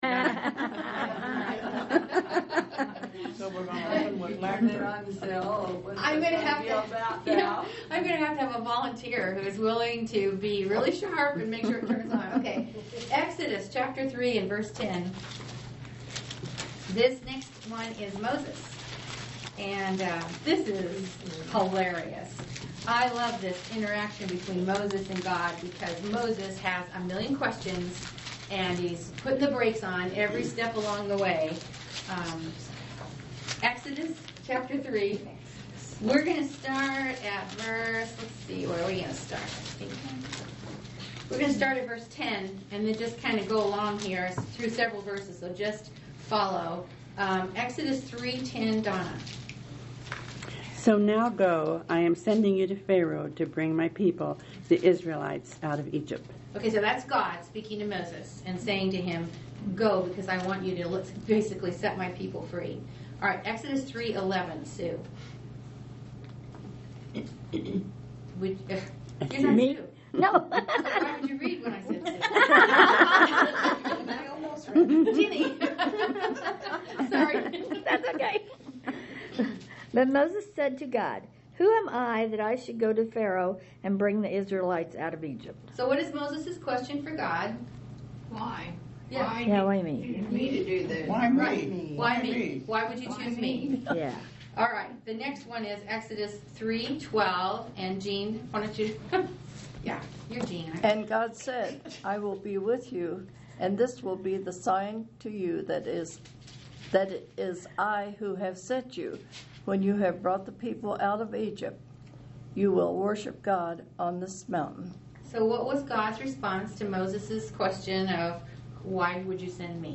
Click on the link below to listen to our group audio Bible Study discussion of this passage.